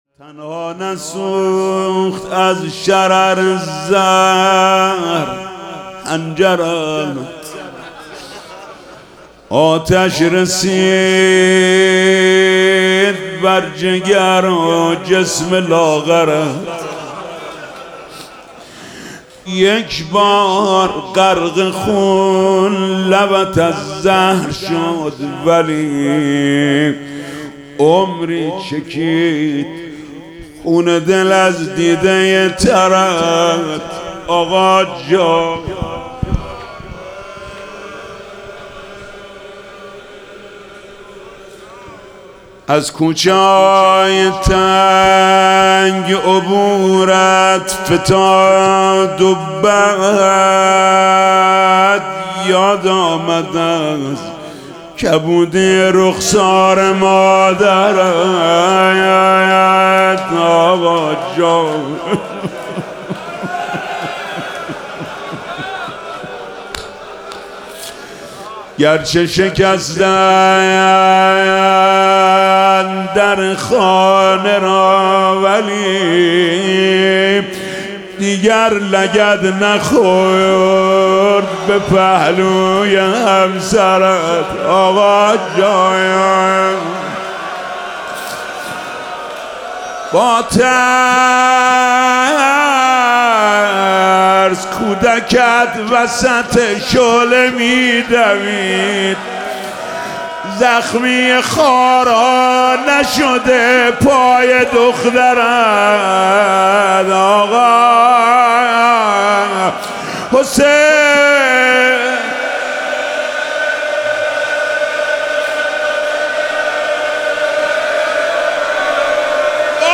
روضه شهادت امام صادق (ع)